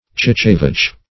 Search Result for " chichevache" : The Collaborative International Dictionary of English v.0.48: Chichevache \Chiche"vache`\, n. [F. chiche lean + vache cow.] A fabulous cow of enormous size, whose food was patient wives, and which was therefore in very lean condition.